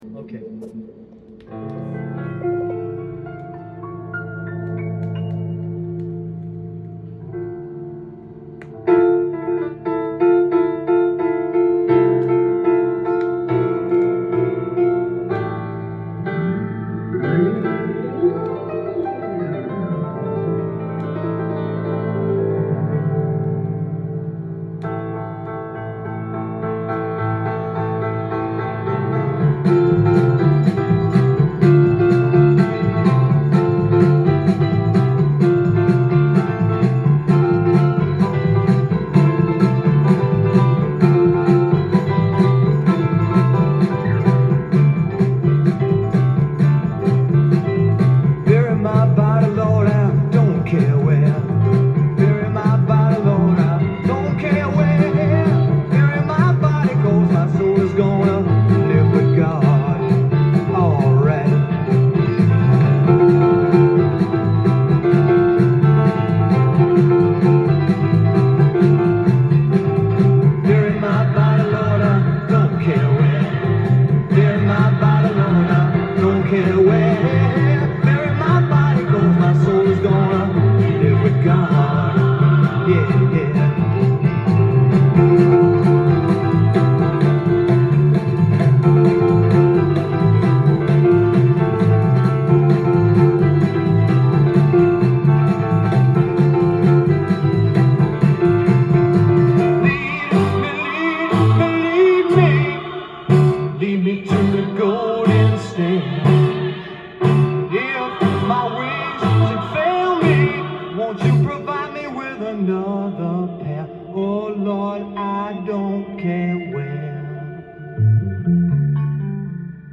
ジャンル：男性ヴォーカル
店頭で録音した音源の為、多少の外部音や音質の悪さはございますが、サンプルとしてご視聴ください。
Backing Vocals
Organ, Piano